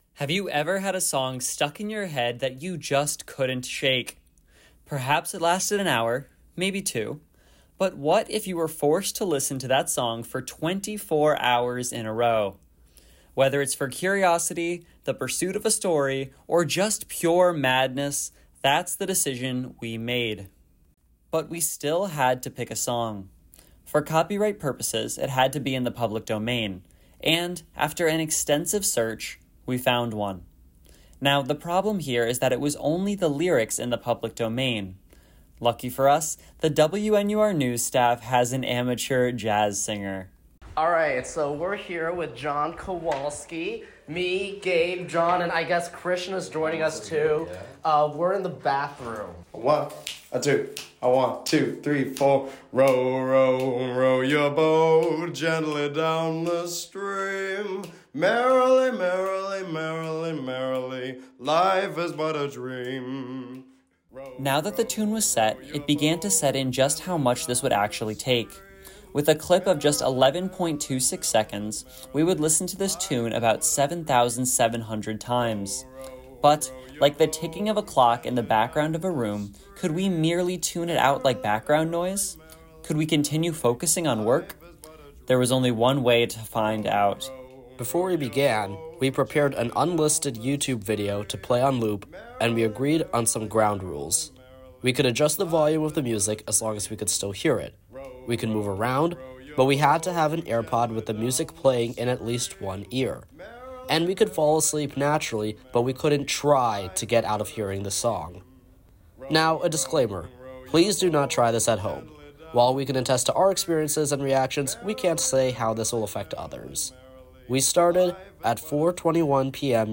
This originally aired as part of our Fall 2024 Special Broadcast: Around the Clock with WNUR News